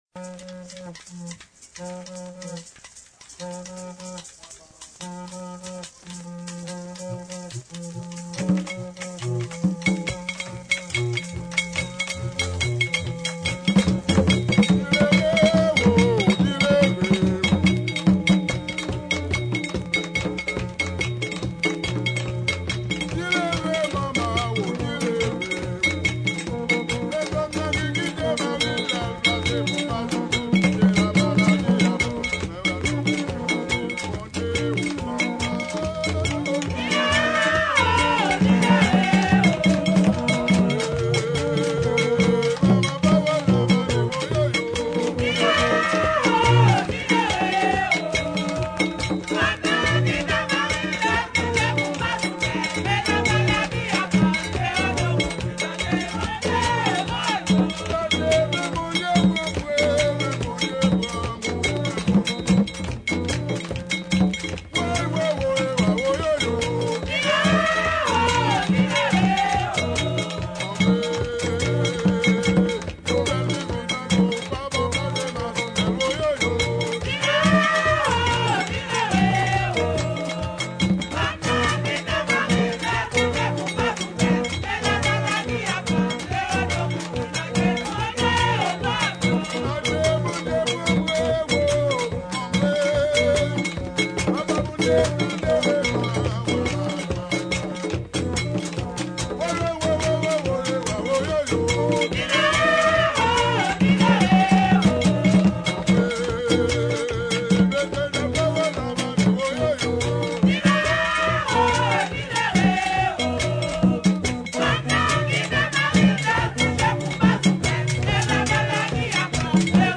2022 RARA D'HAITI (VACCINE, FLOKLORE HAITIEN) audio closed https